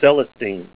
Say CELESTINE Help on Synonym: Synonym: Celestite   ICSD 28055   PDF 5-593